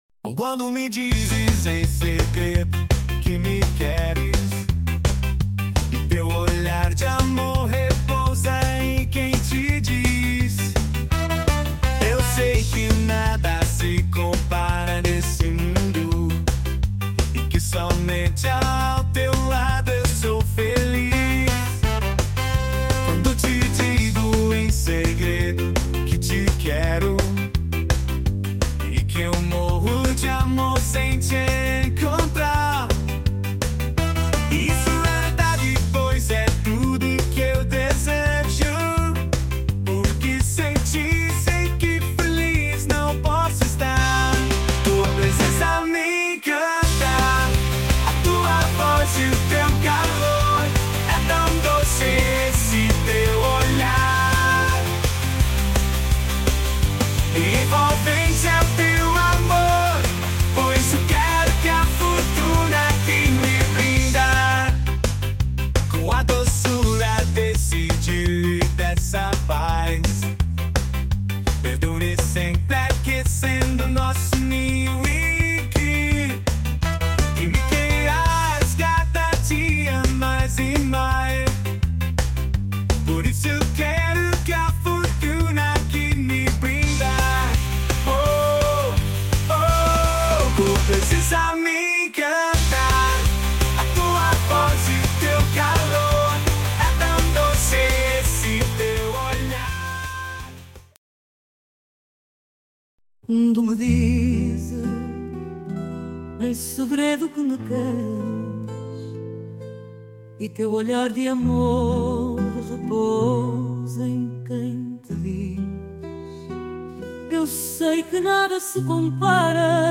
com melodias cointinuadas por AI